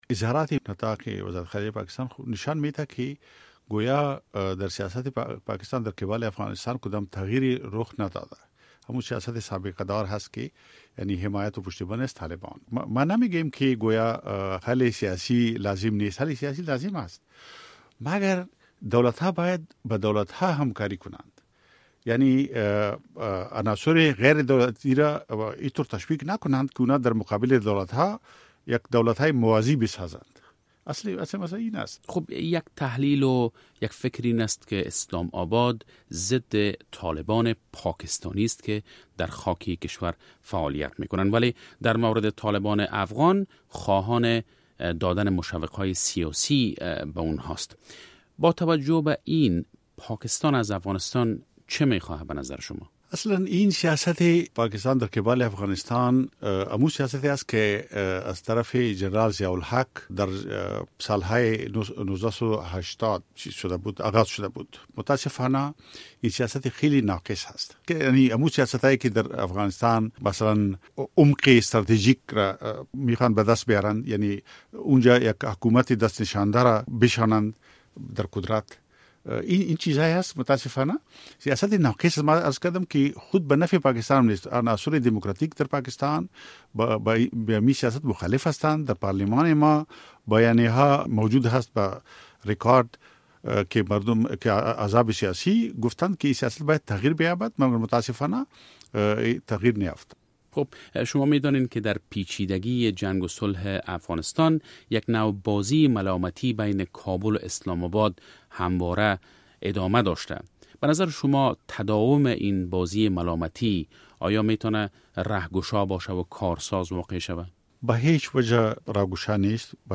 مصاحبه - صدا
افراسیاب ختک سیاستمدار پاکستانی و عضو پیشین سنای این‌کشور